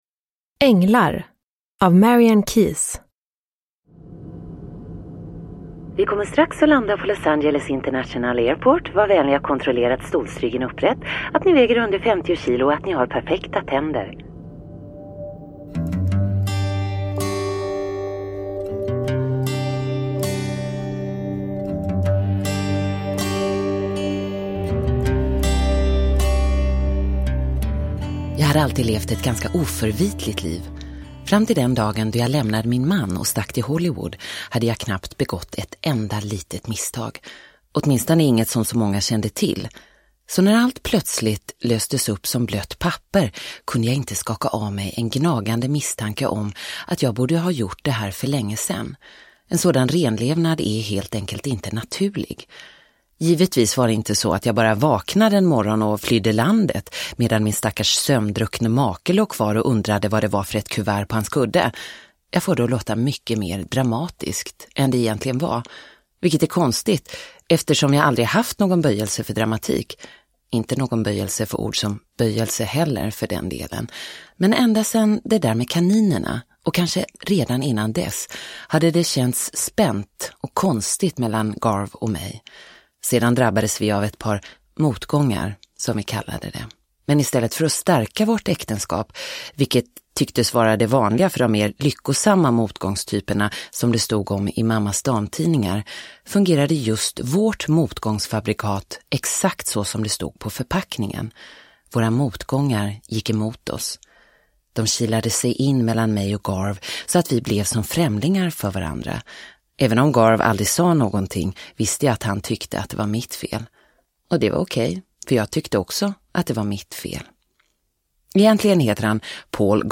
Änglar – Ljudbok – Laddas ner